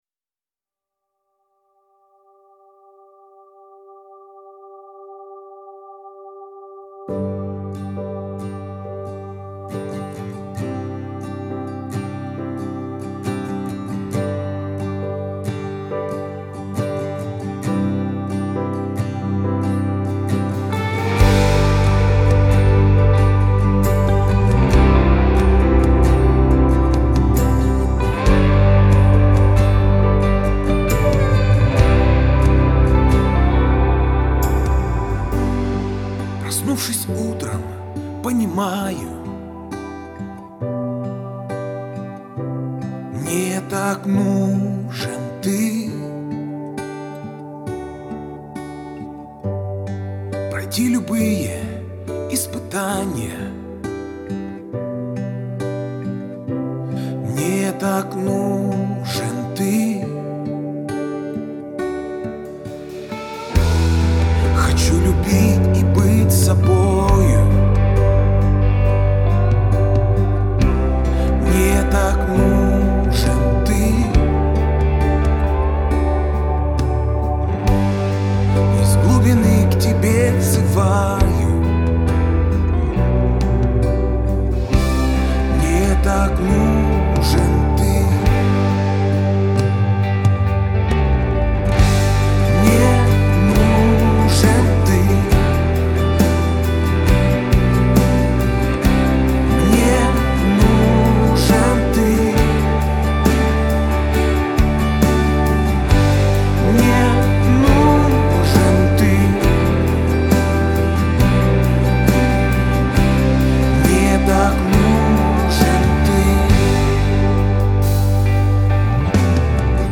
1832 просмотра 1130 прослушиваний 109 скачиваний BPM: 136